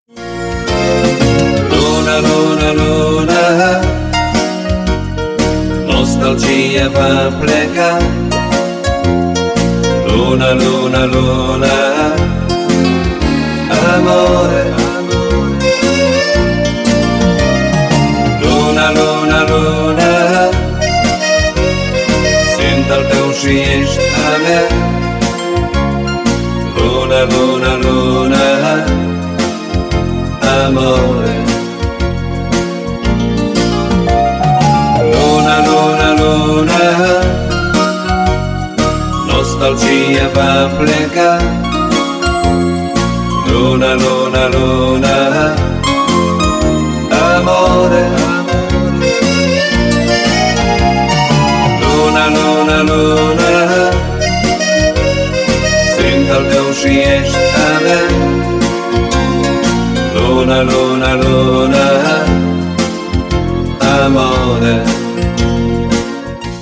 • Качество: 320, Stereo
красивые
спокойные